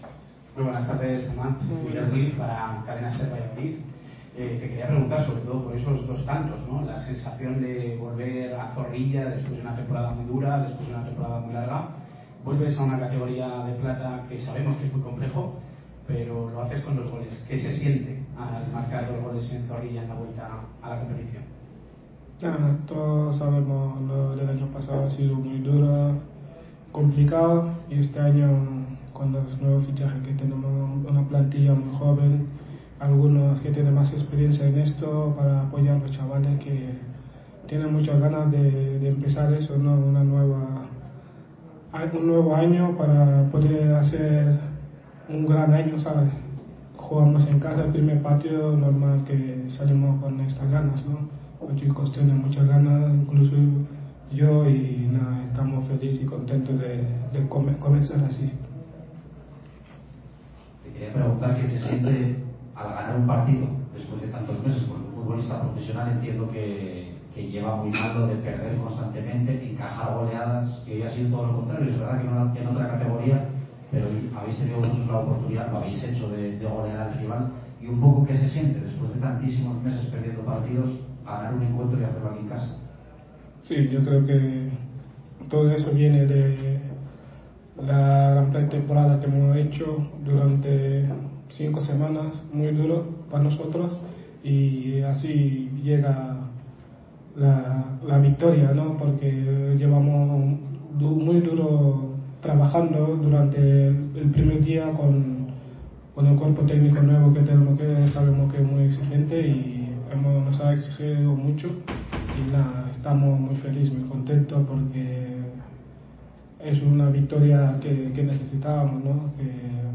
Ruedas de prensa
aquí la rueda de prensa completa